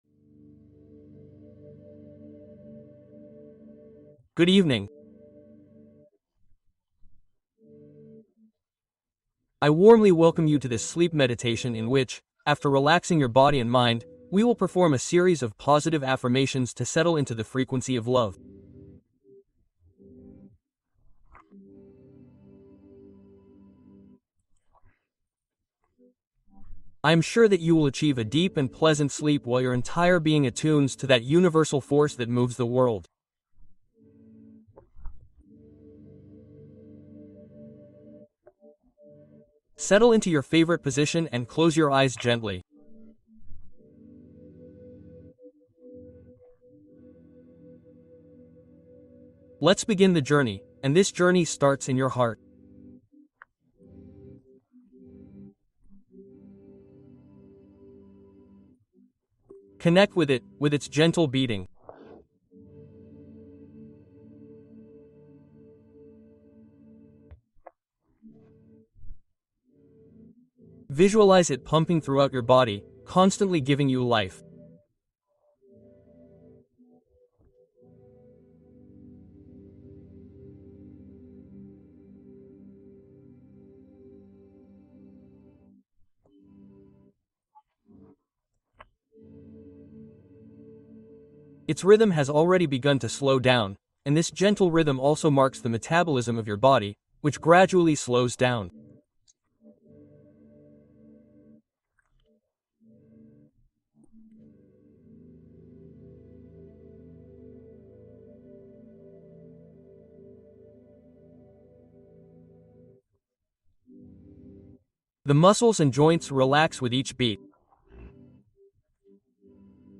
Meditación de Amor: Afirmaciones para la Noche